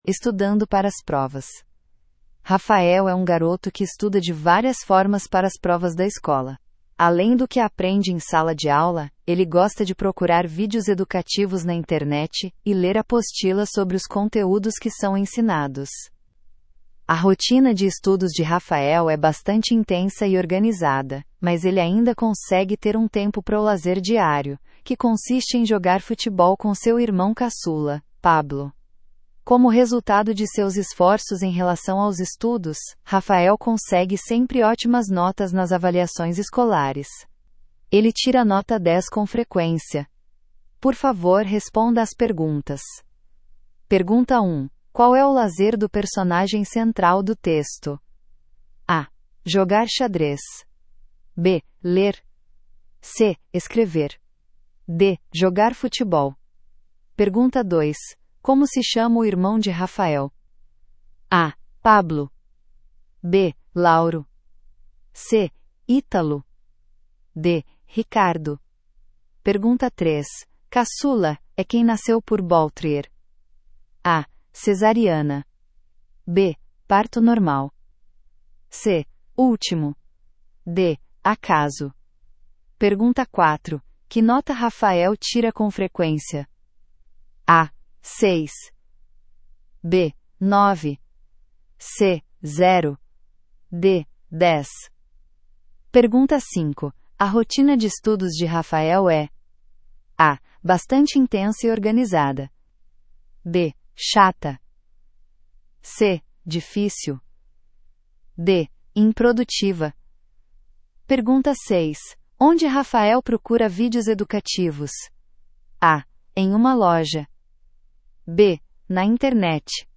Brasil